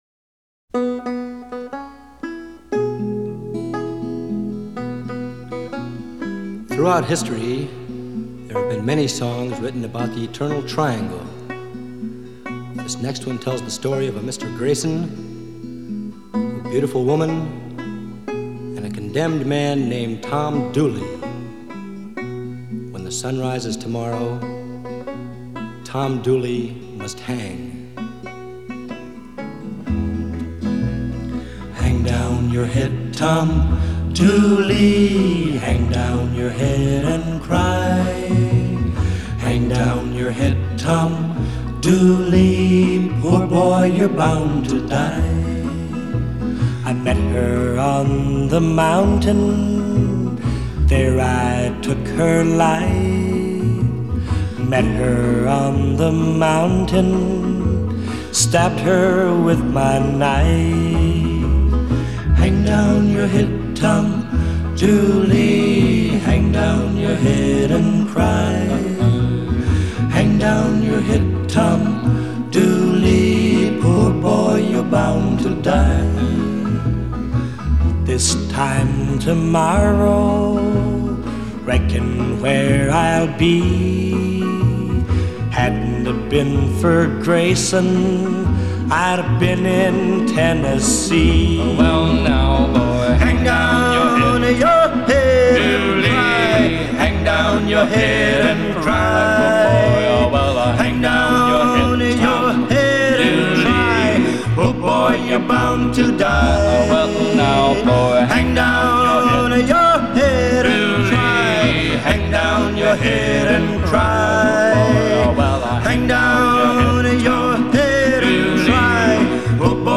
歌曲曲调优美，朗朗上口，也许您可以惬意地哼上几句！